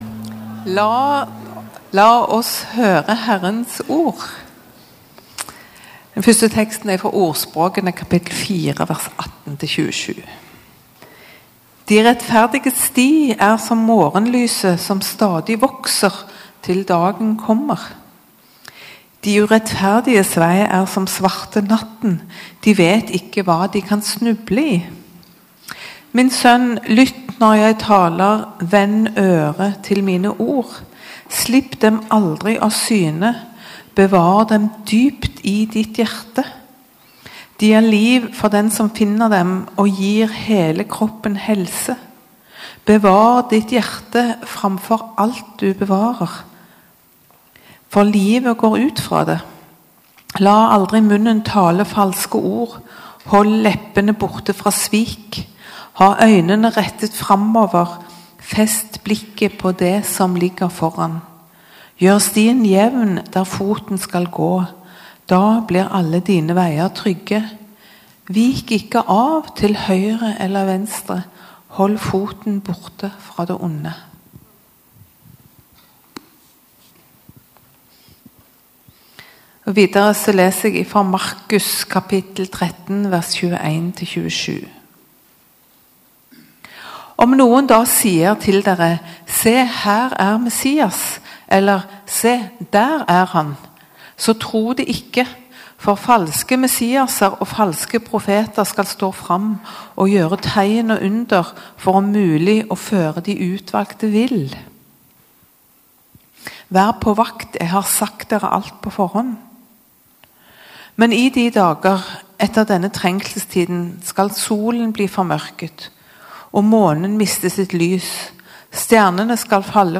1 Gudstjeneste 9. februar 2025, -6 søndag i åpenbaringstiden 25:57